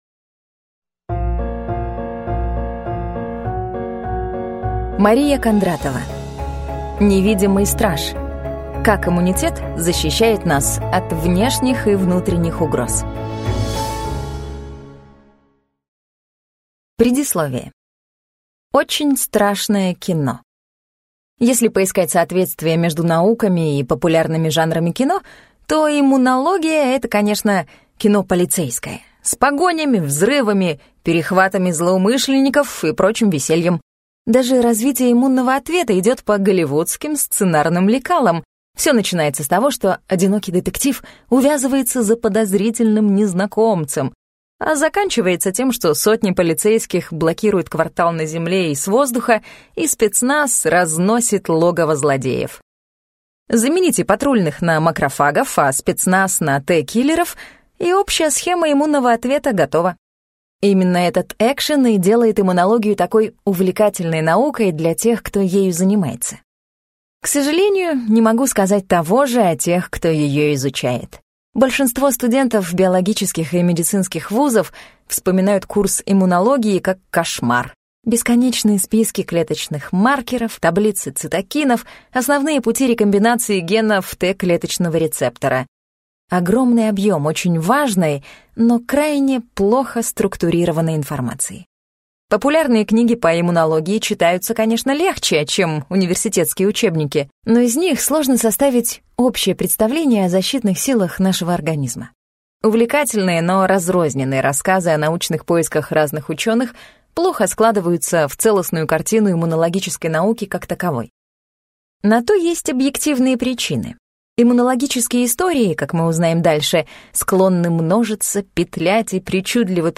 Аудиокнига Невидимый страж: Как иммунитет защищает нас от внешних и внутренних угроз | Библиотека аудиокниг